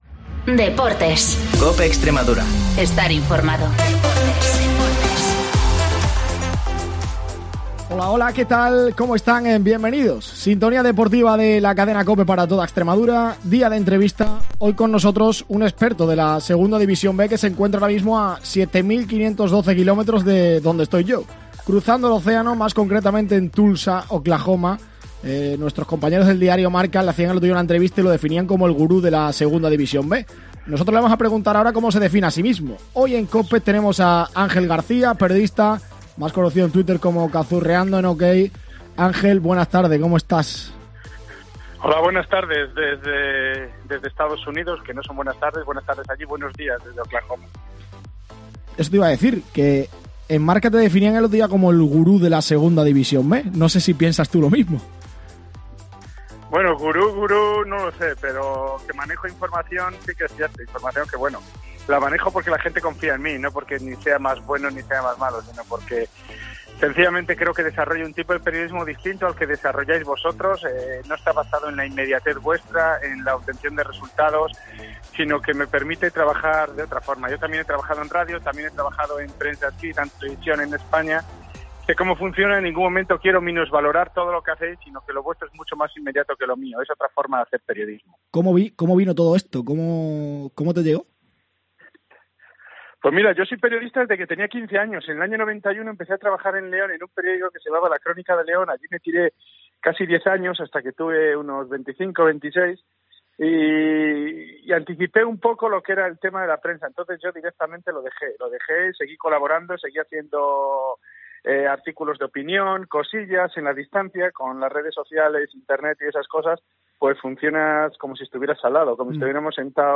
Entrevista a